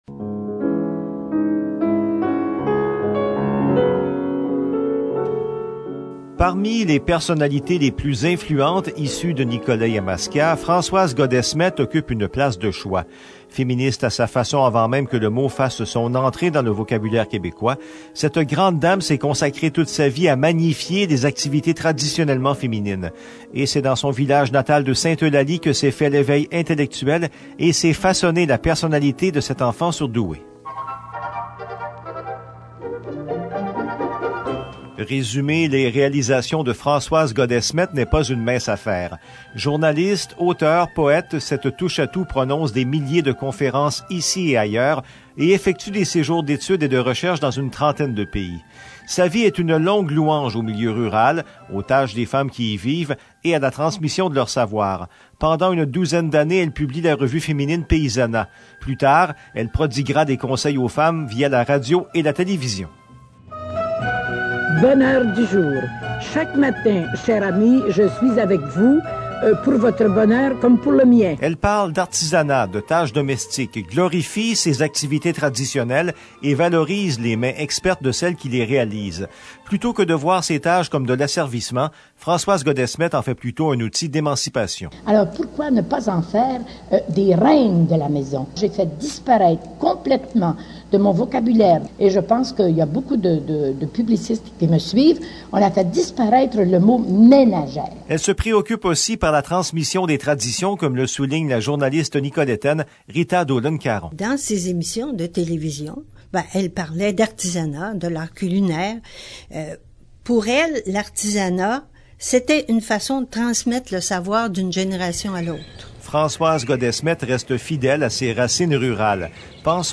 Cette capsule historique fait partie d’un projet initié par la MRC de Nicolet-Yamaska dans le cadre de son Entente de développement culturel avec le ministère de la Culture et des Communications et réalisé de concert avec VIA 90.5 FM.